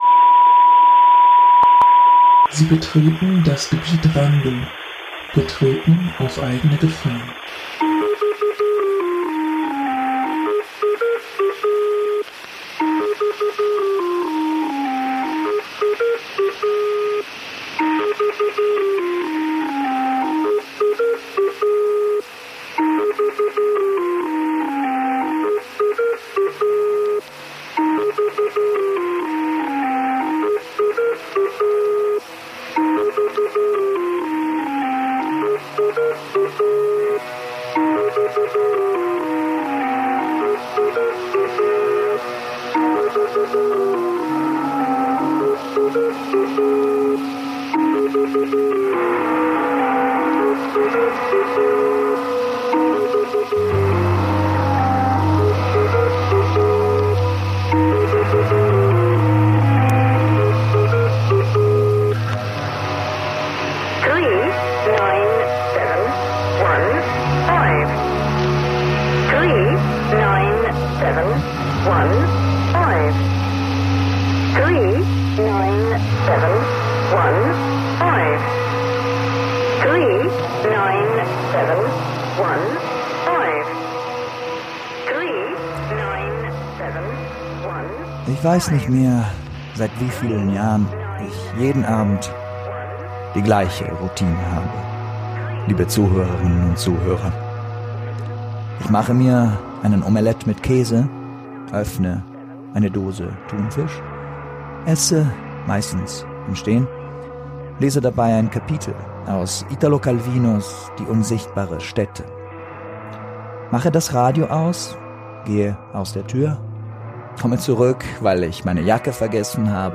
SFX: The Lincolnshire Poacher